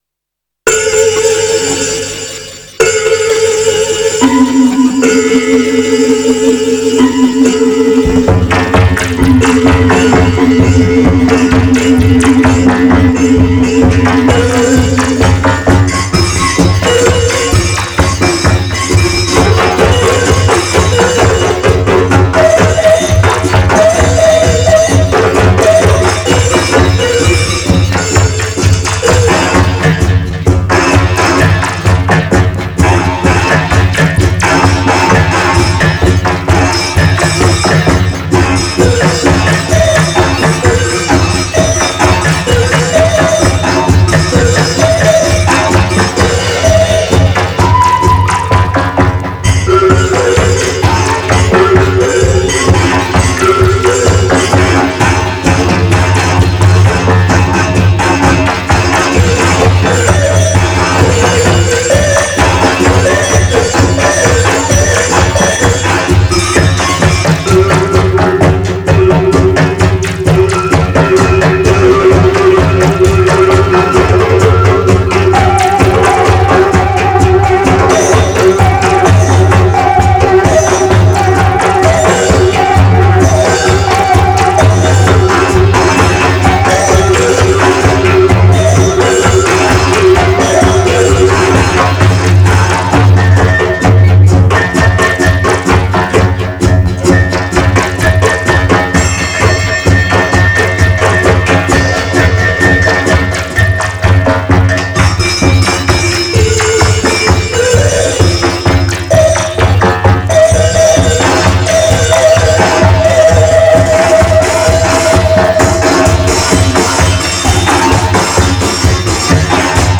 indus, proto-techno, France, 1983